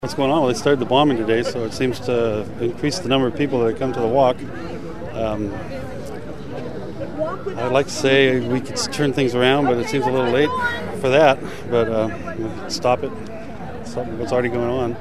Oakland Antiwar Demo Sound 2